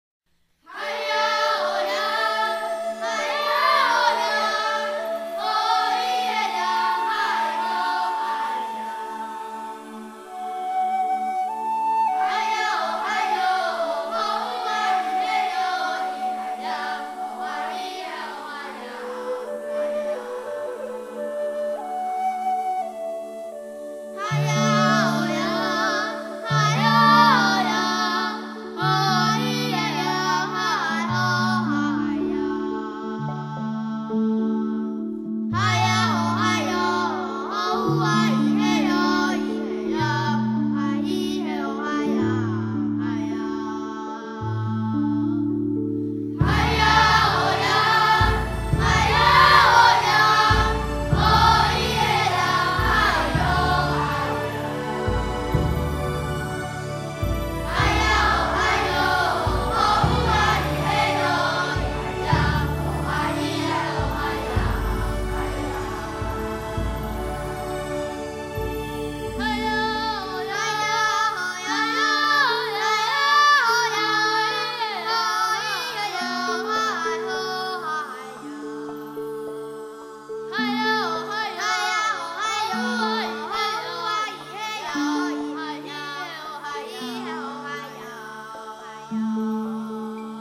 遇見另一角落的童聲吟唱
原住民青少年兒童合唱音樂的收錄珍
台中縣花東、自強新村原住民與平地小孩的聯合演唱